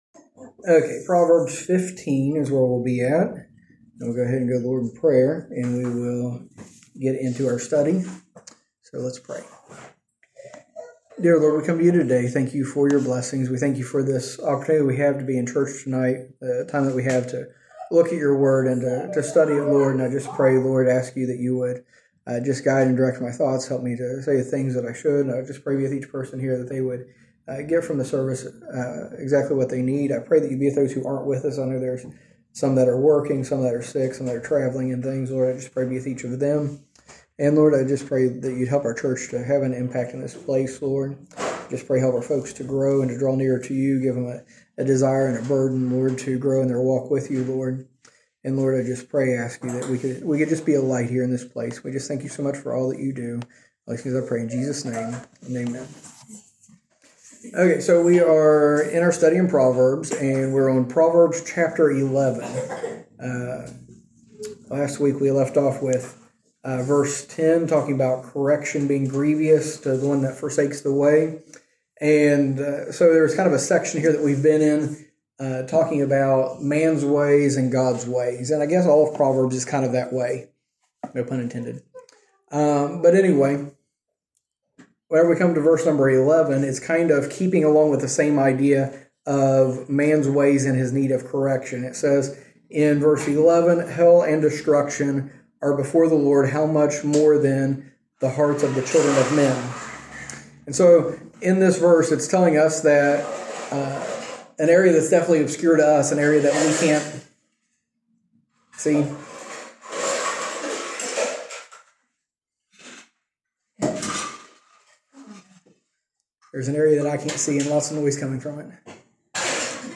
Message
A message from the series "Proverbs."